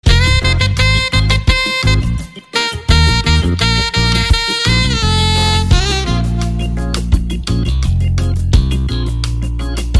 • Качество: 128, Stereo
громкие
без слов
инструментальные
джаз
Бодренький гудок для настоящего джазиста